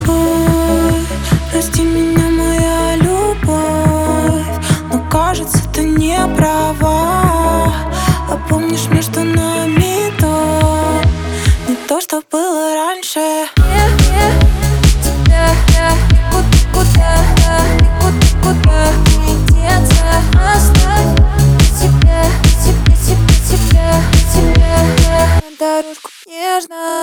поп
грустные
битовые